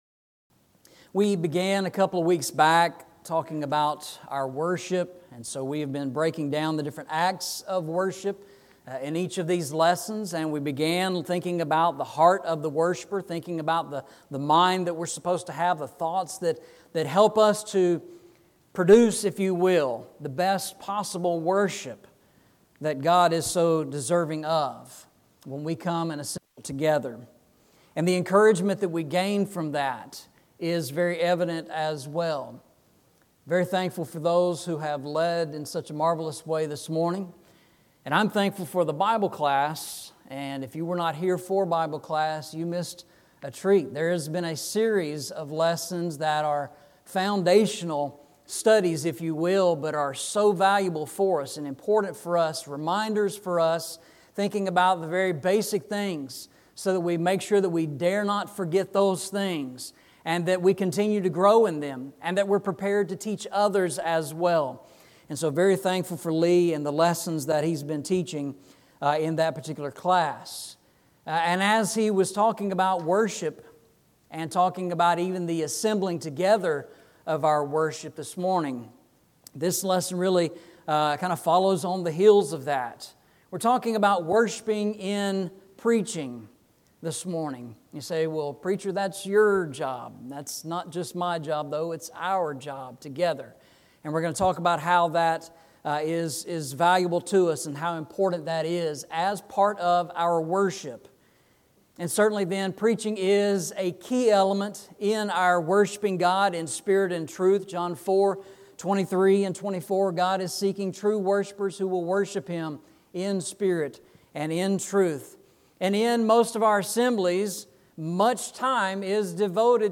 Eastside Sermons
Service Type: Sunday Morning